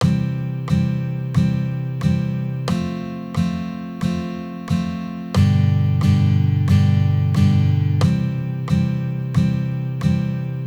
First, the I-IV-V chord progression in C Major.
I-IV-V Chord Progression